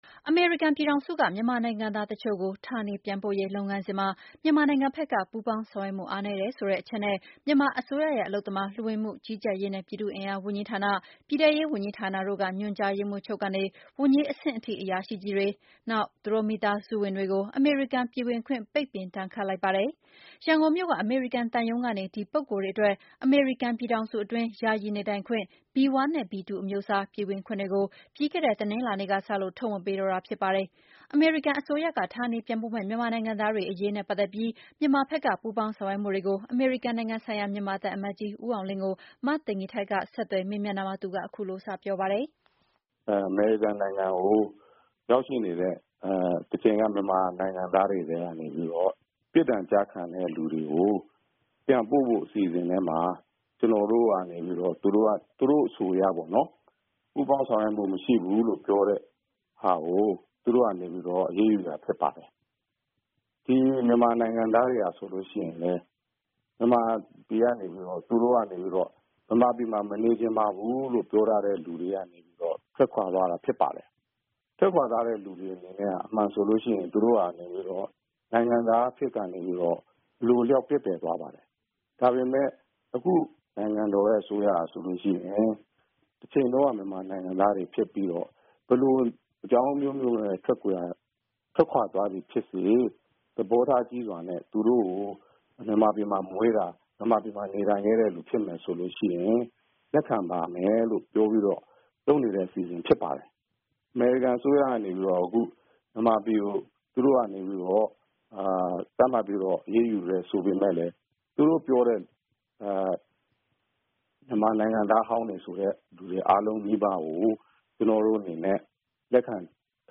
အမေရိကန်အရေးယူမှု မြန်မာသံအမတ်ကြီးနဲ့မေးမြန်းချက်